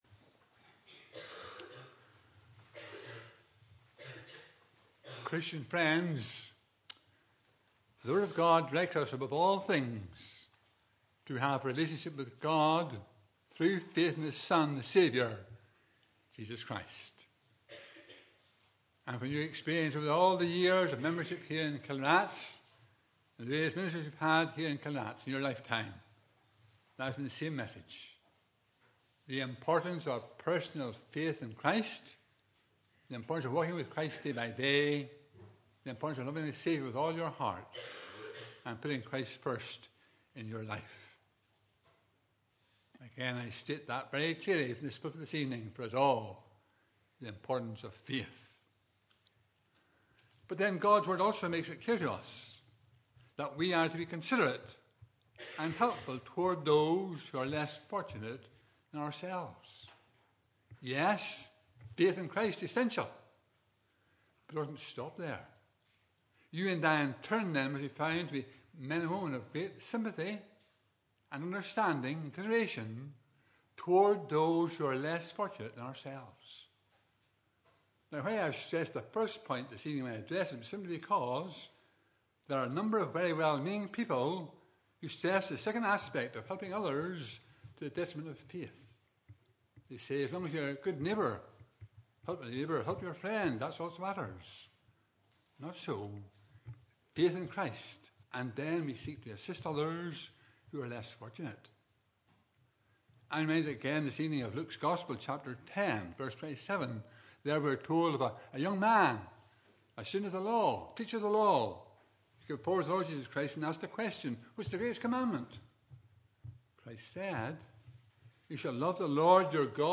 Passage: 2 Samuel 9:1-13 Service Type: Evening Service